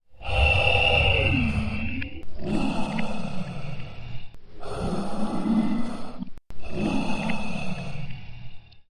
0492Breath.ogg